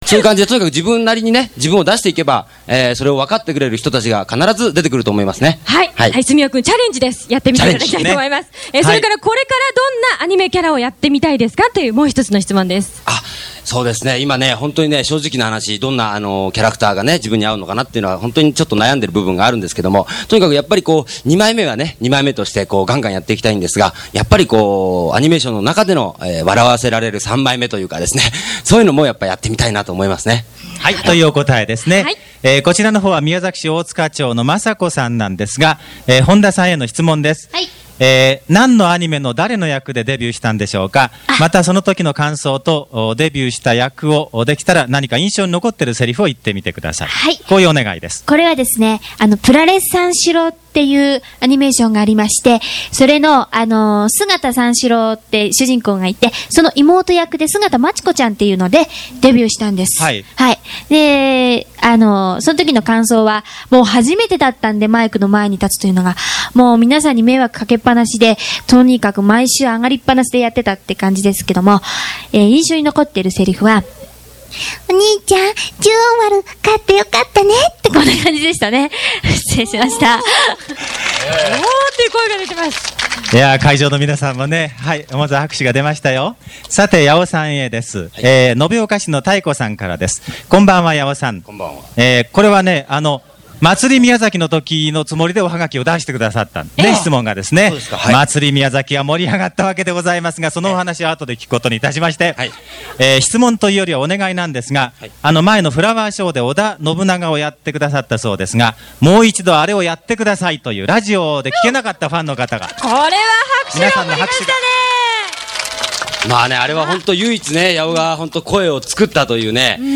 夏のえびの高原です。
さすがプロですね！その声優の技倆を聞かせるセリフでした！